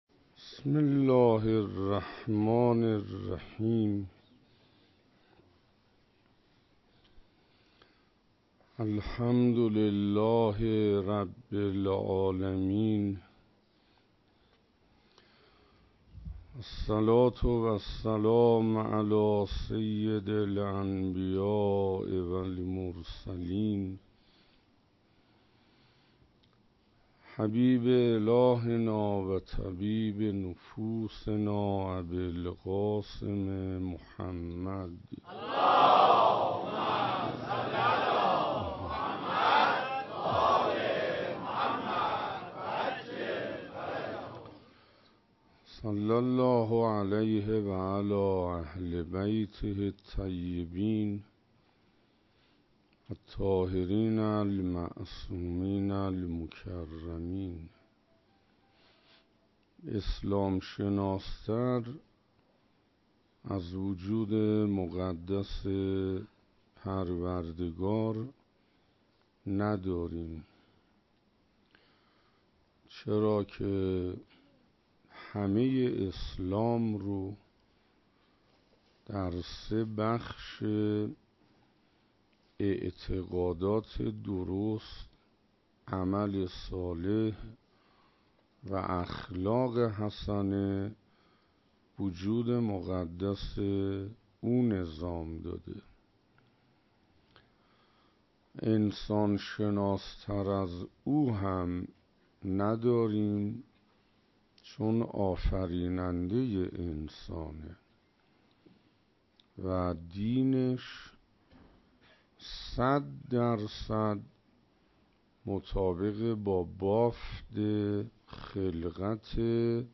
شب هشتم محرم 96 - حسینیه حضرت ابالفضل علیه السلام (تهرانپارس)